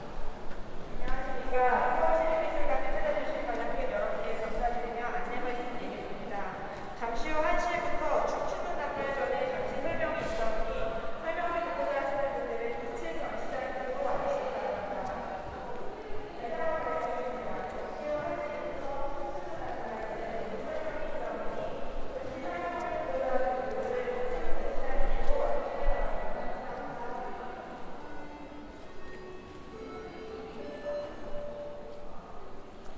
로비전시안내딩동댕.mp3